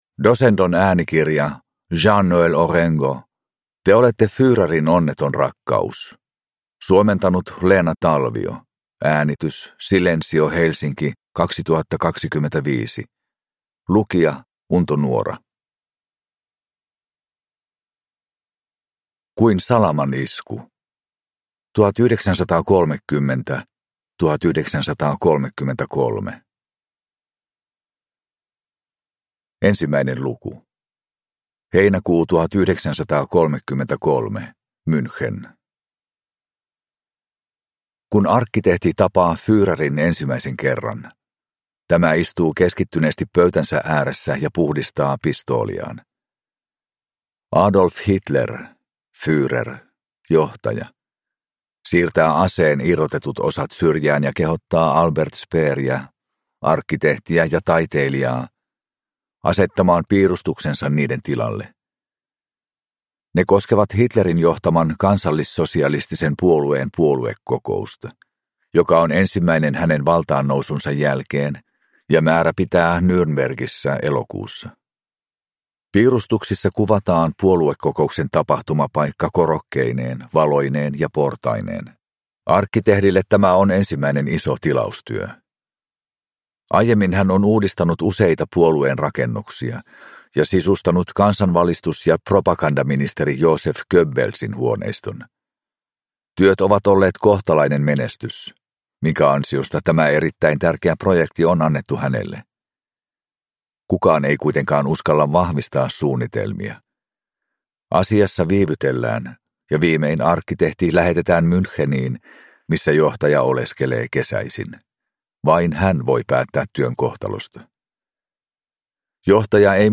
Te olette Führerin onneton rakkaus (ljudbok) av Jean-Noël Orengo